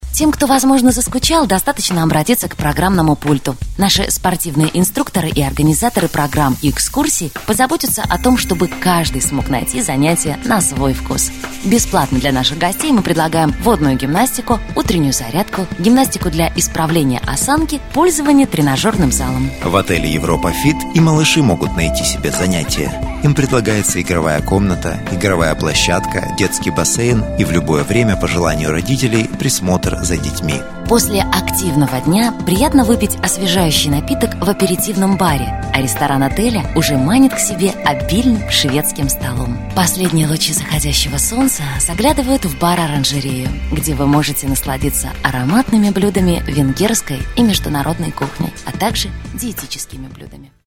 2 диктора, М+Ж, русский язык.